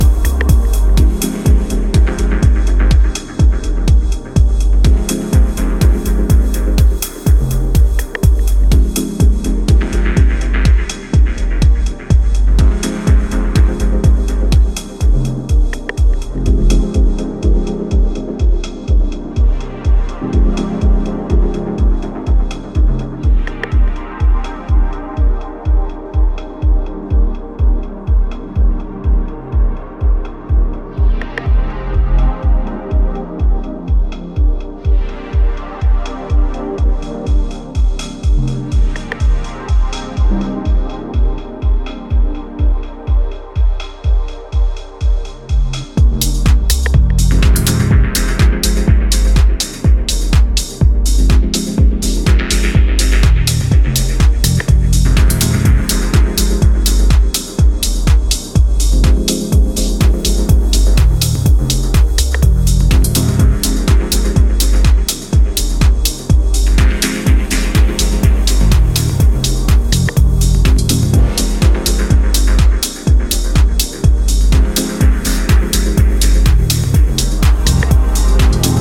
dubby